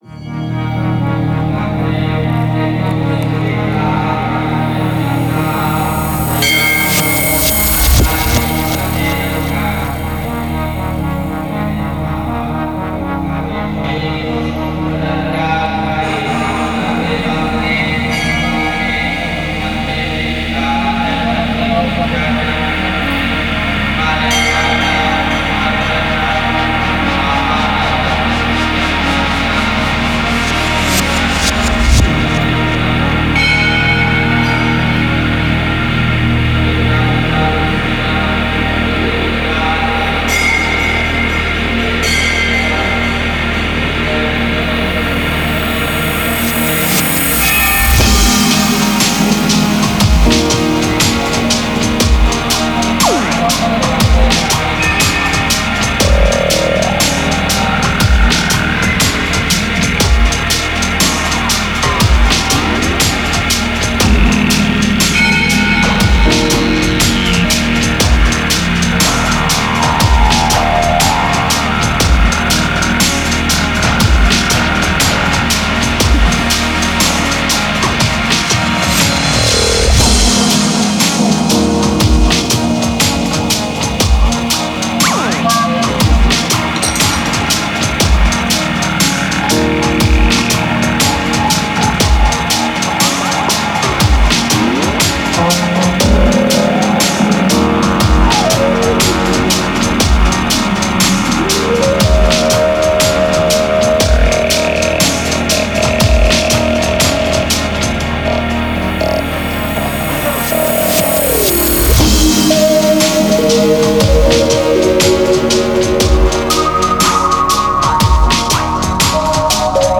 Genre: Electro.